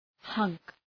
{hʌŋk}